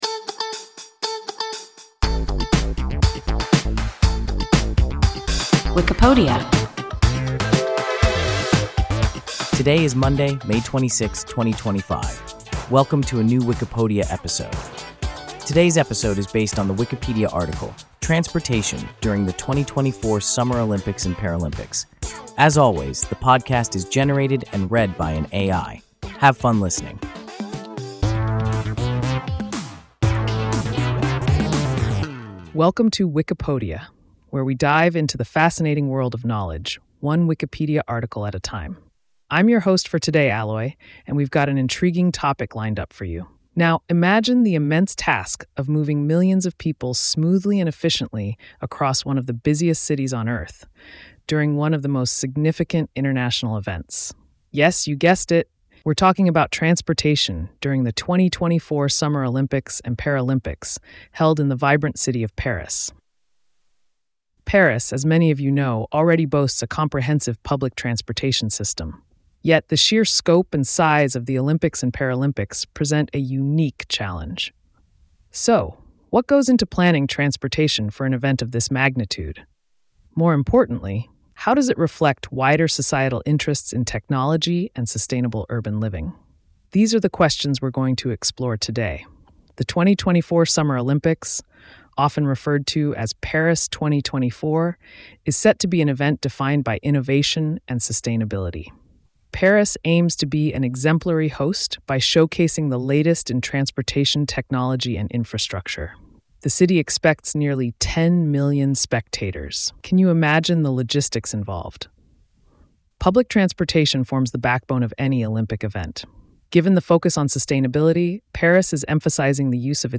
Transportation during the 2024 Summer Olympics and Paralympics – WIKIPODIA – ein KI Podcast